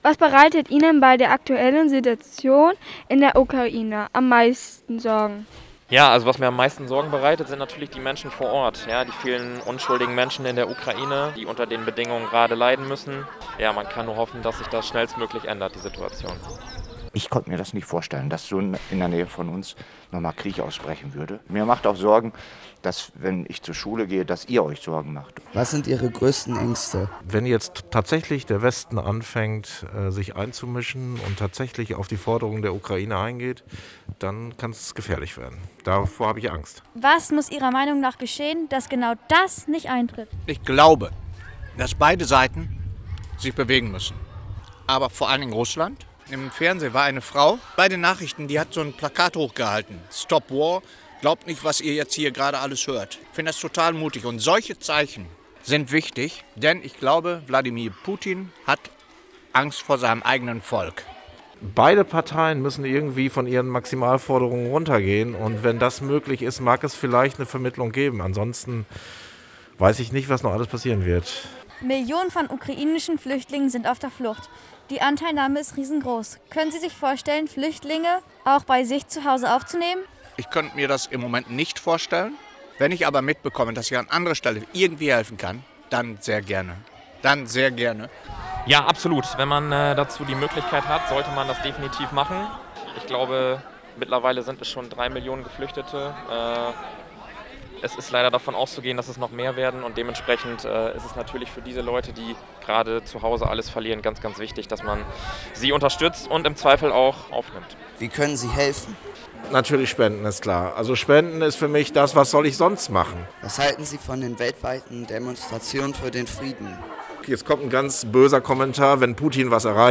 Umfragen zum Krieg in der Ukraine
Diese liefen am 17. und 31. März in der Schulradio-Sendung „Zoom!“ und sind im Folgenden noch einmal nachzuhören.
Einschätzungen und Meinungen einiger Lehrer*innen